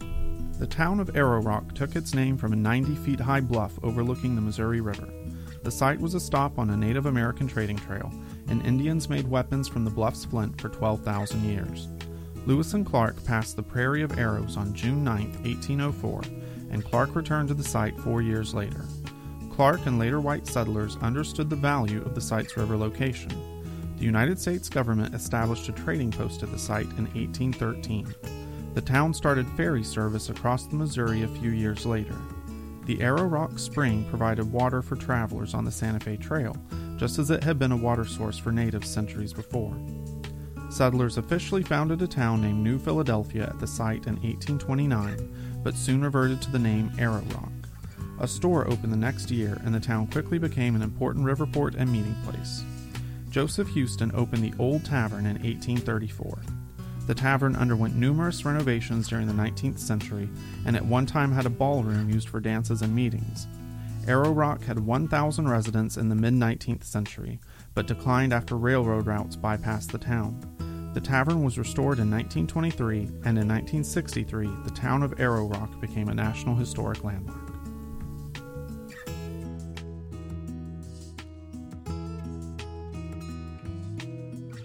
Researched, written, and narrated by University of West Florida Public History Student